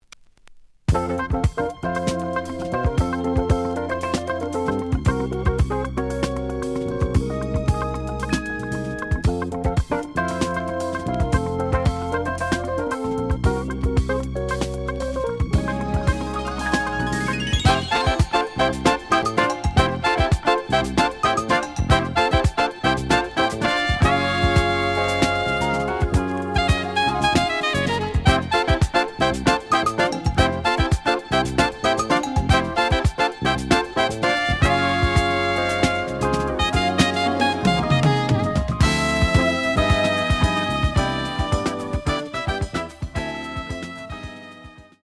The audio sample is recorded from the actual item.
●Genre: Jazz Funk / Soul Jazz
Slight edge warp.